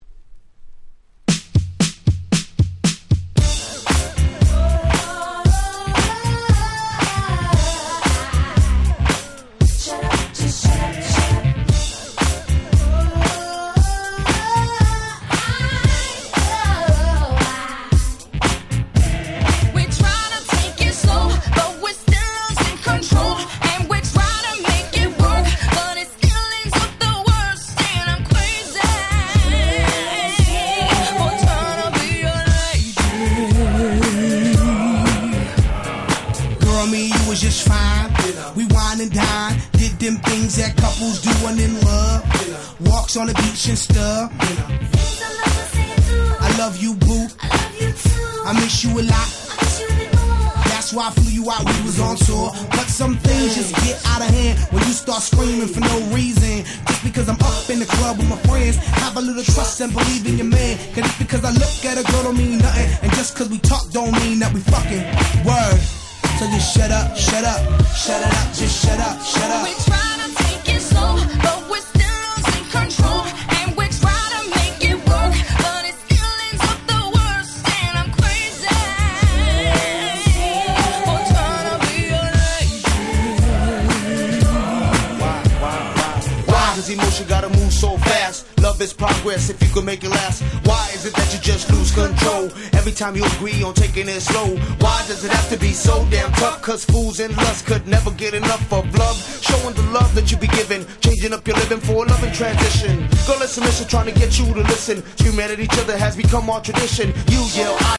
03' Super Hit Hip Hop !!
Disco Funk